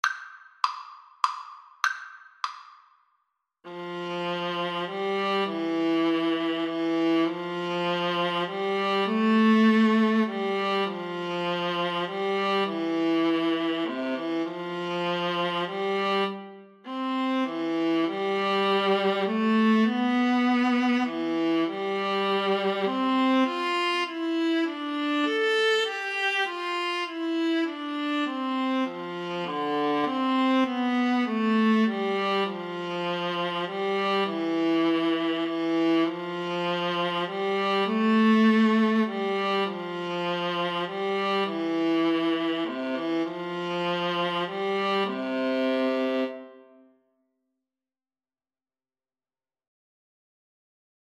Viola 1Viola 2
3/4 (View more 3/4 Music)
Viola Duet  (View more Easy Viola Duet Music)
Classical (View more Classical Viola Duet Music)